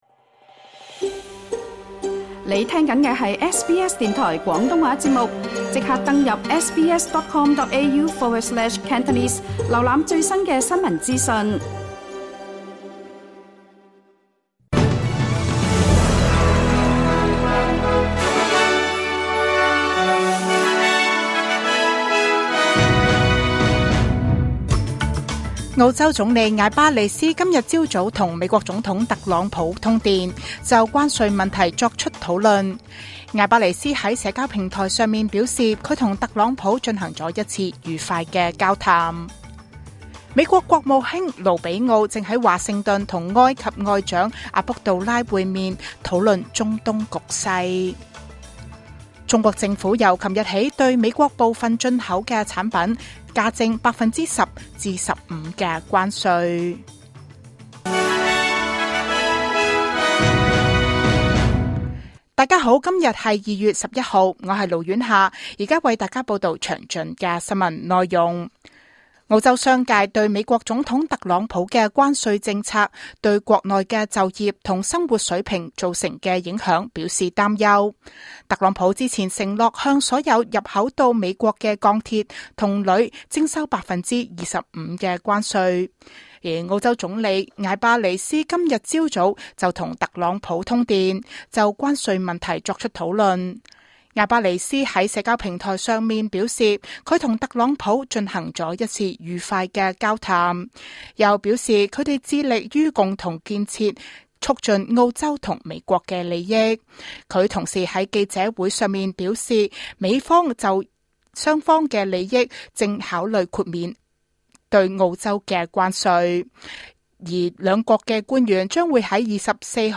2025 年 2月 11 日 SBS 廣東話節目詳盡早晨新聞報道。